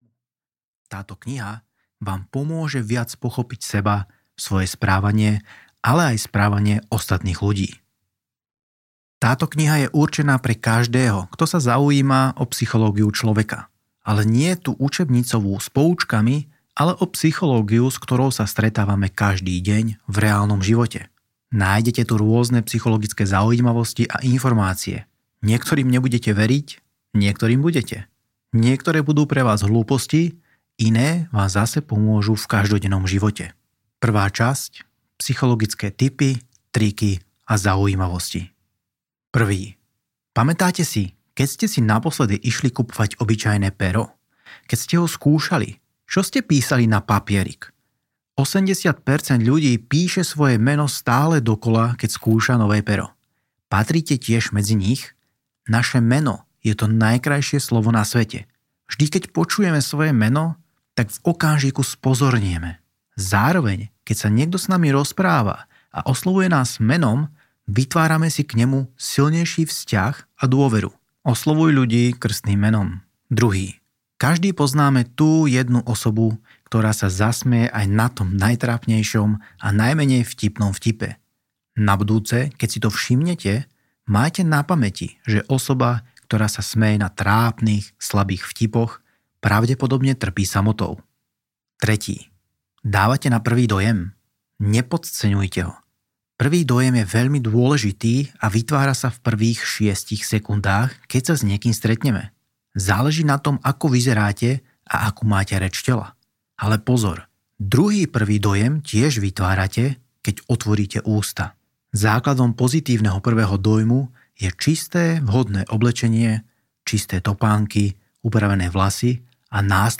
Psychológia ako zbraň audiokniha
Ukázka z knihy